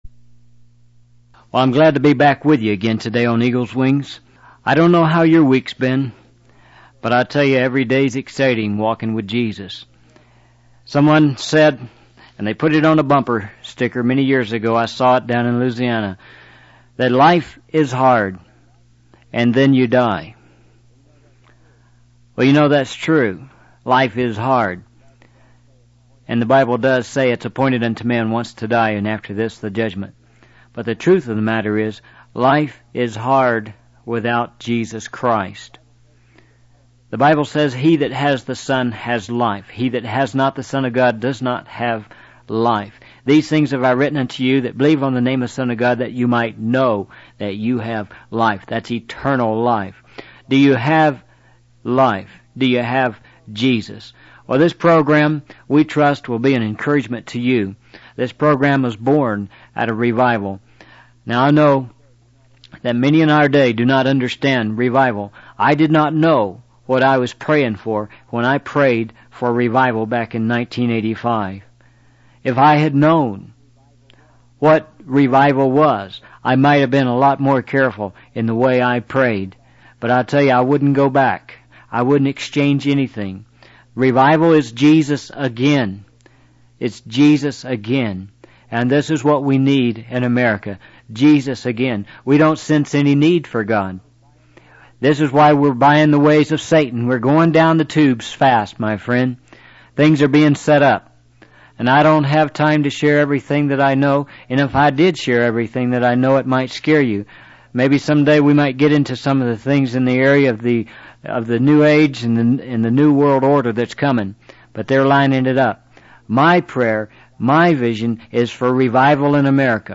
In this sermon, the speaker shares a personal experience where God revealed to him the perversion of Bible versions. He emphasizes that the presence of confusion in churches is a characteristic of Satan, which is caused by the various versions of the Bible available today.